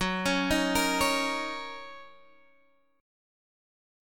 Listen to Gb6 strummed